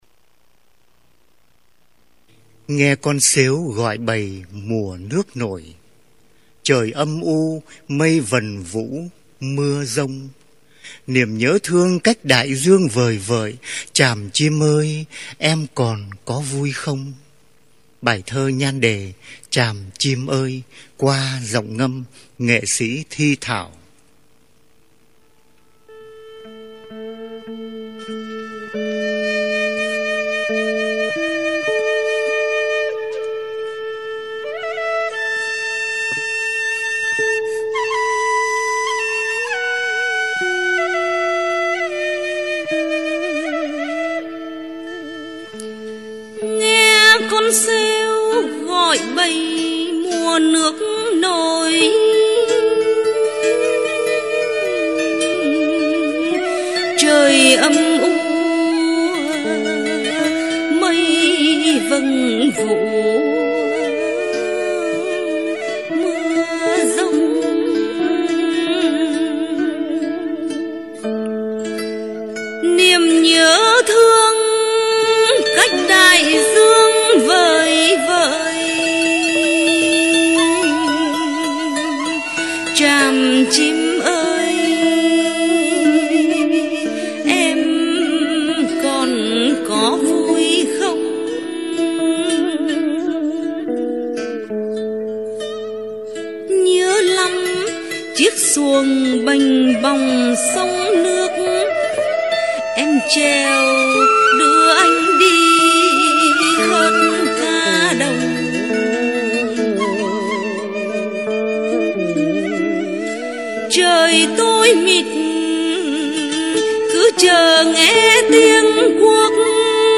Ngâm Thơ ” Bài Thơ “Tràm Chim Ơi “